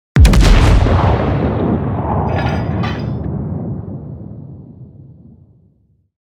Реалистичные эффекты с хорошей детализацией низких частот.
Грозный звук выстрела из огромной пушки